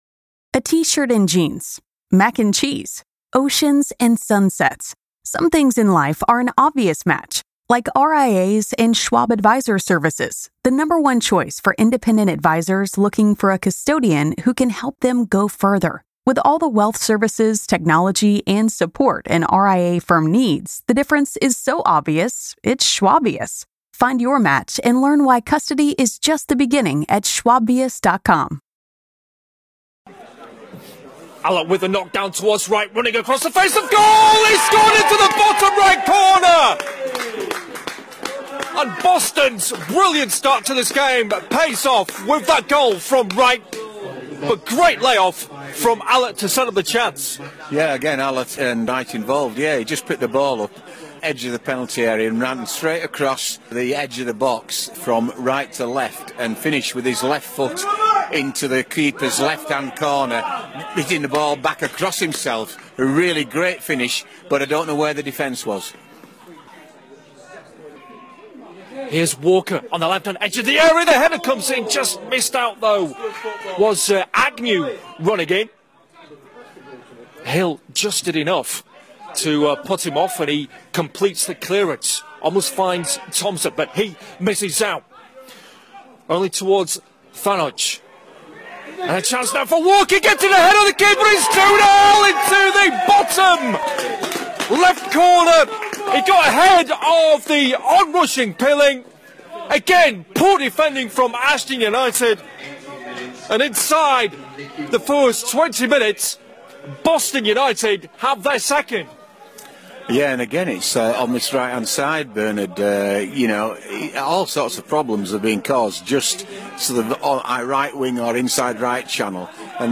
Highlights